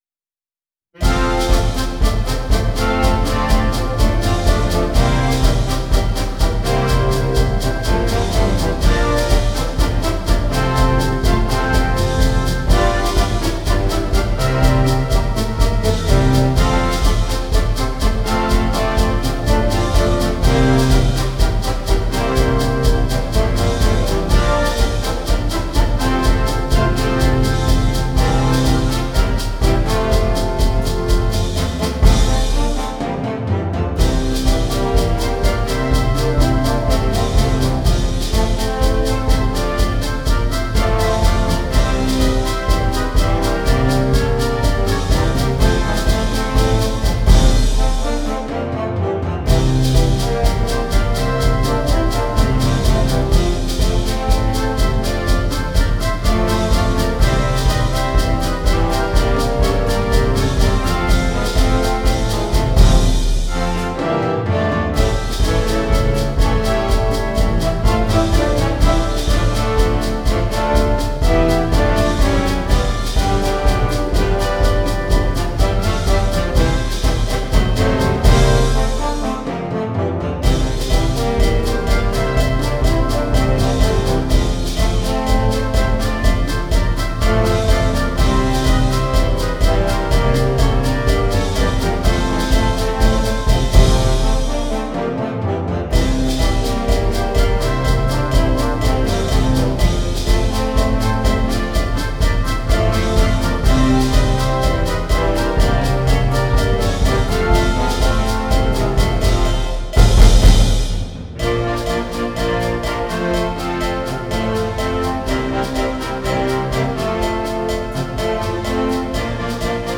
brani costruiti ad organico variabile